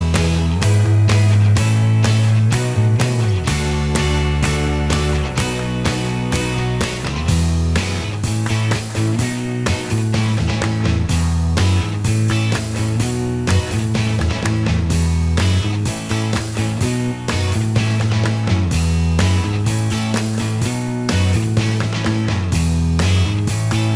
Minus Lead Guitar Rock 3:12 Buy £1.50